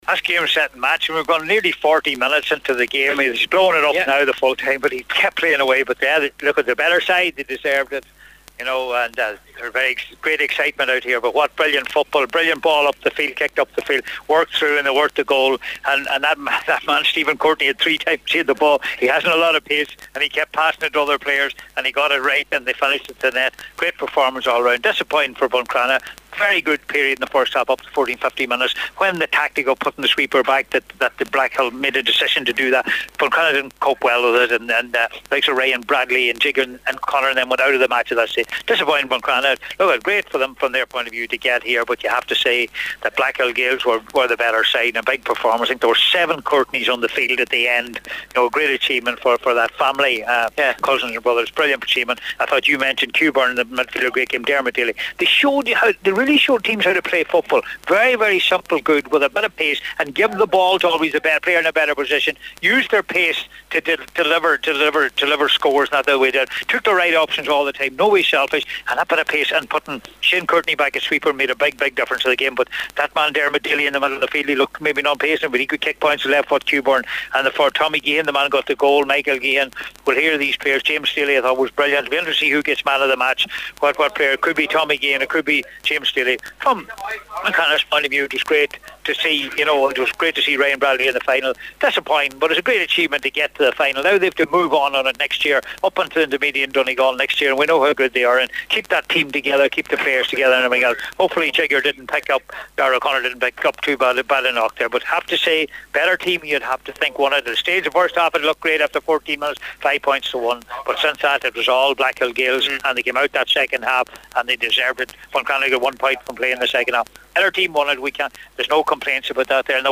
report from Healy Park…